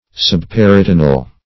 Search Result for " subperitoneal" : The Collaborative International Dictionary of English v.0.48: Subperitoneal \Sub*per`i*to"ne*al\, a. (Anat.) Situated under the peritoneal membrane.
subperitoneal.mp3